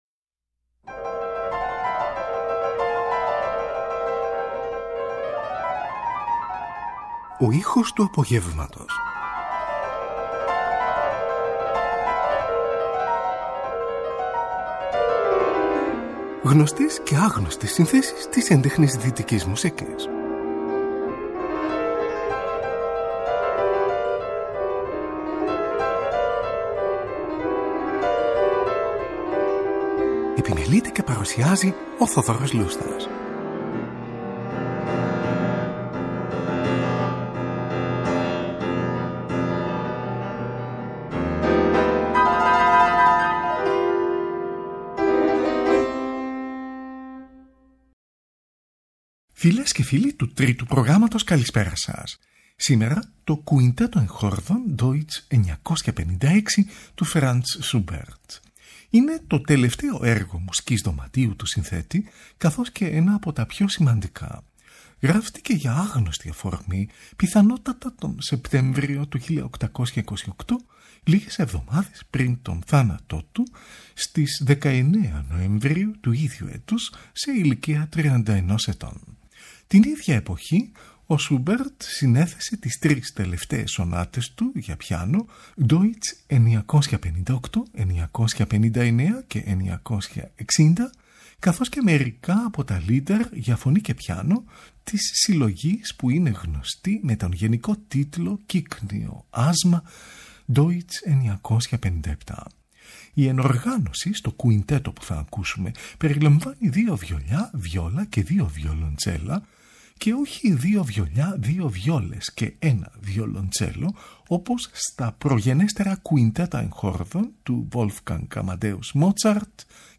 από δισκογραφική εργασία του 1950
δεύτερο βιολοντσέλο
πρώτο βιολί
δεύτερο βιολί
βιόλα
Κουιντετο για Εγχορδα σε Ντο Μειζονα